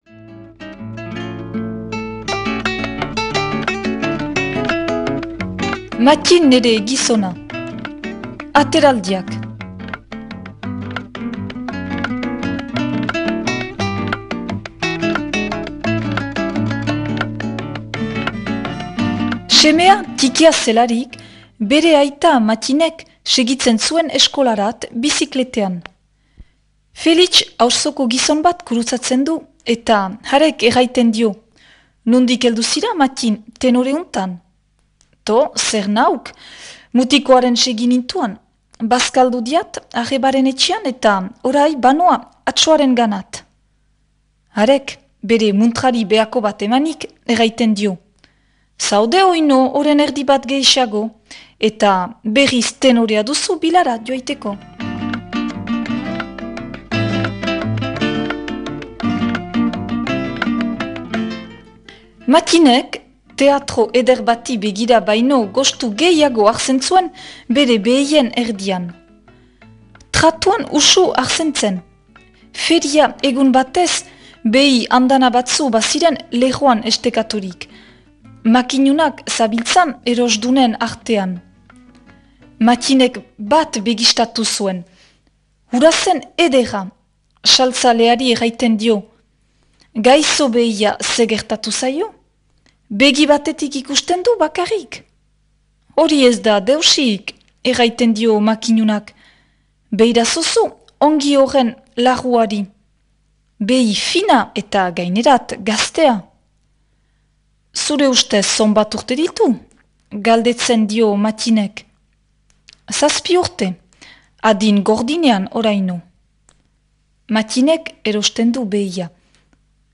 irakurketa